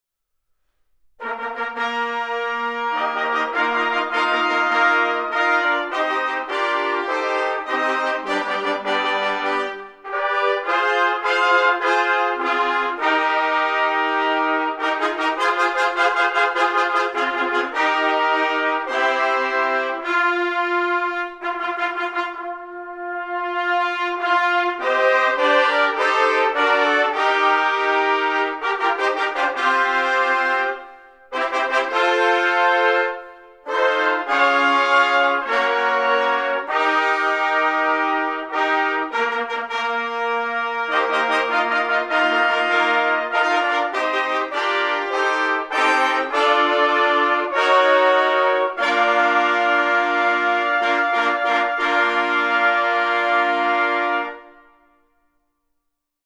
Trumpet Ensemble